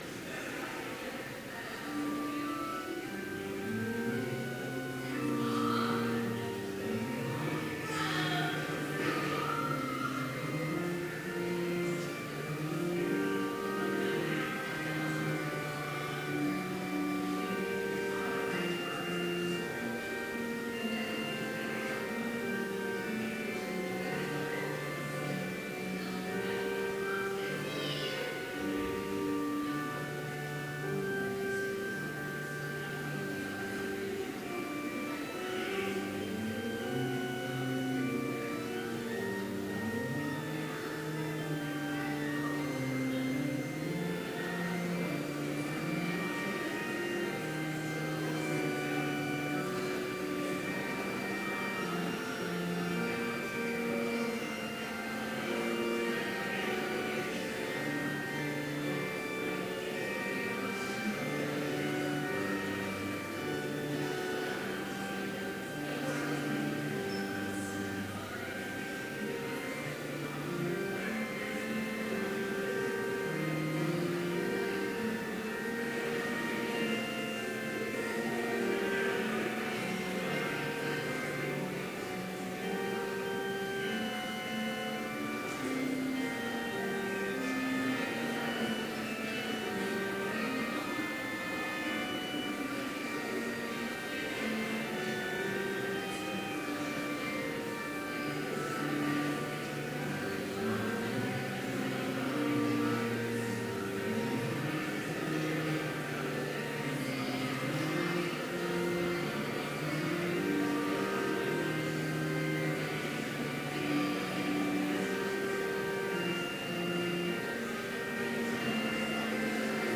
Complete service audio for Chapel - September 27, 2017
Hymn 61, vv. 1-3, My Heart Is Longing Reading: Matthew 6:19-21